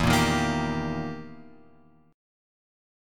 EmM7b5 chord {0 1 1 3 x 3} chord